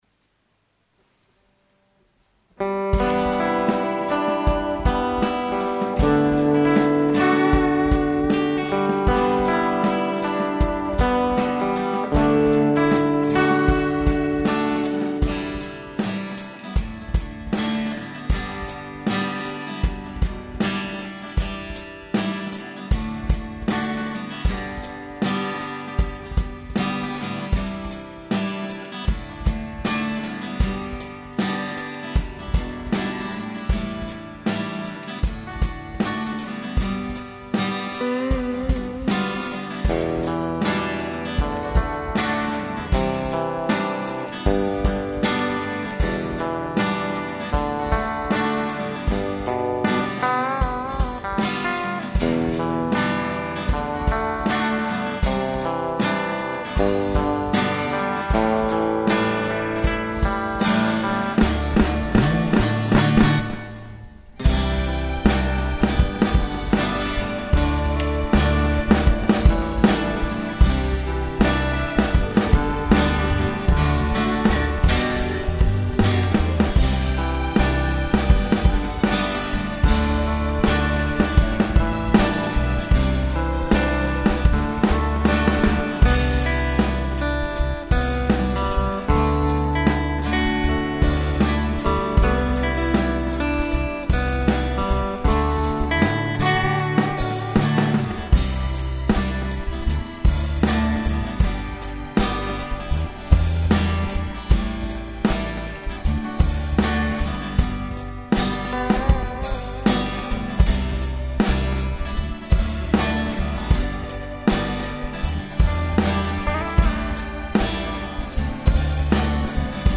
(^^;;特に古い音源はパソコンのハム音が混入して聞き難いです・・・、ご容赦を。
ドラムをイーブンに打ち直しました。